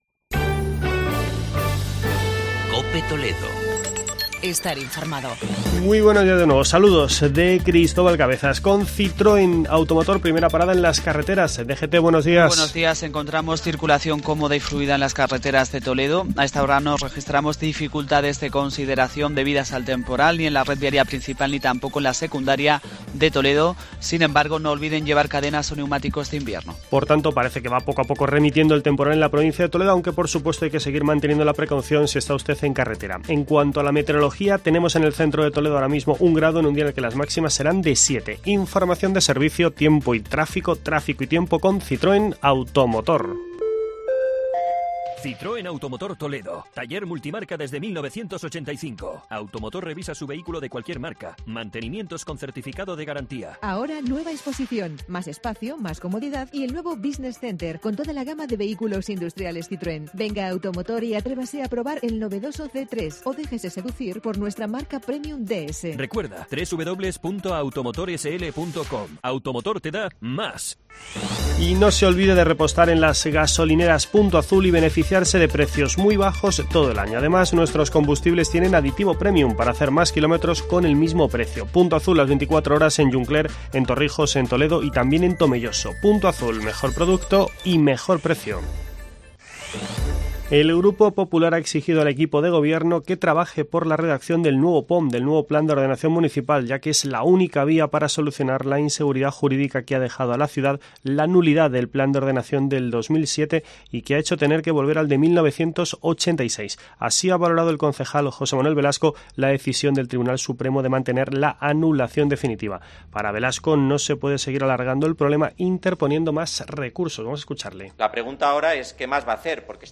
informativo matinal de la Cadena COPE.